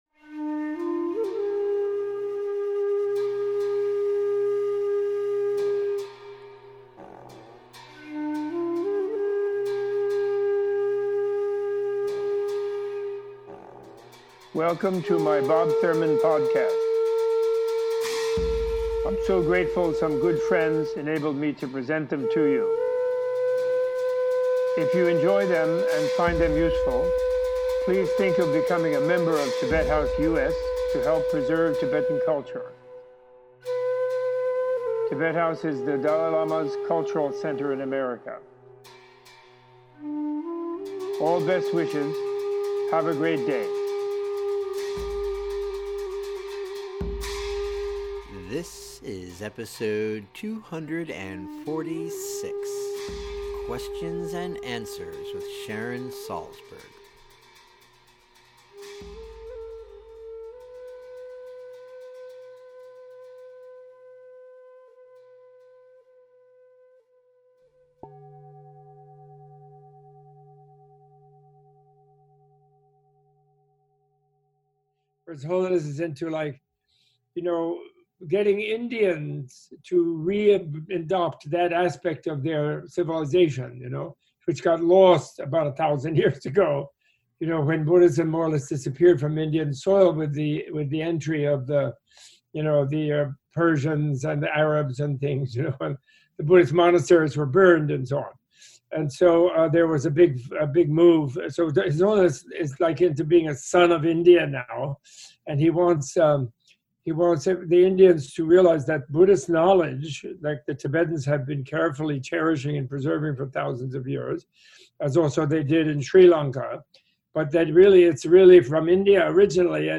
Tibet-House-US-Menla-Conversation-Q-A-with-Sharon-Salzberg-Ep.-246.mp3